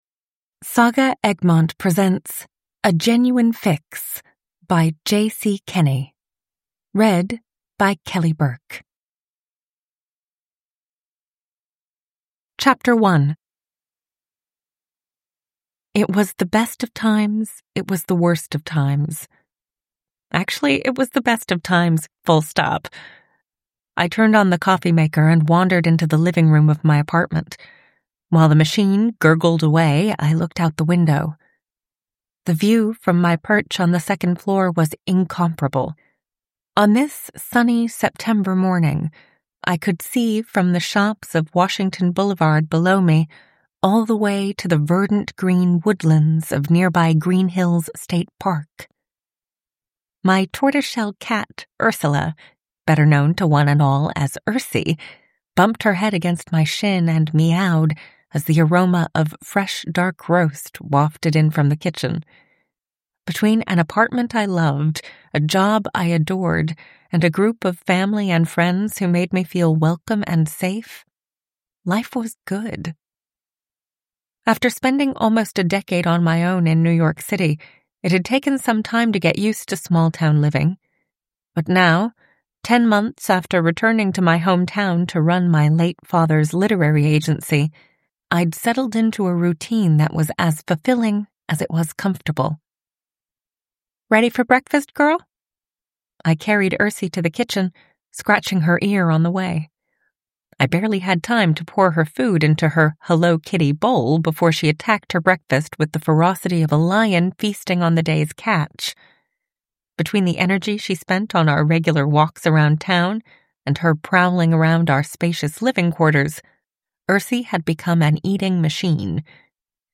A Genuine Fix: A lighthearted, small town cozy crime with a literary agent sleuth (ljudbok) av J. C. Kenney